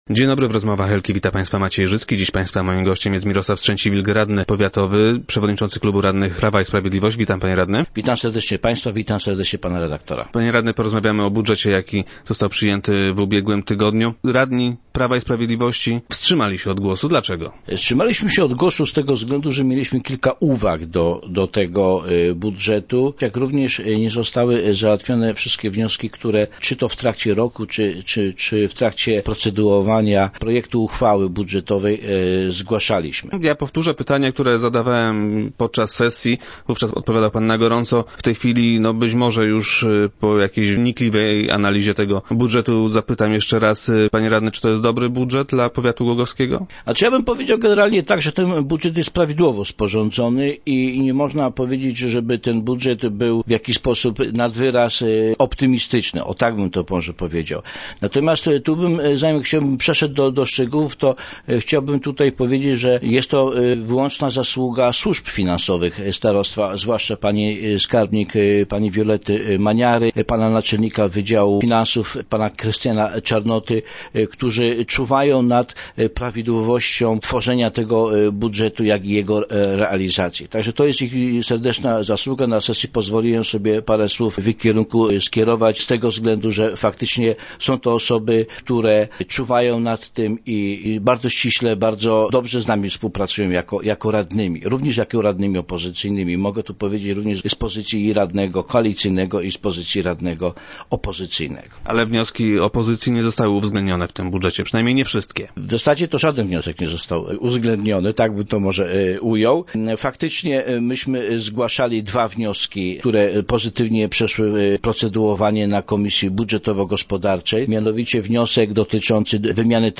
Dziwią się jednak, że żaden z wniosków opozycji nie został w nim uwzględniony. Gościem Rozmów Elki był Mirosław Strzęciwilk, przewodniczy klubu radnych PiS.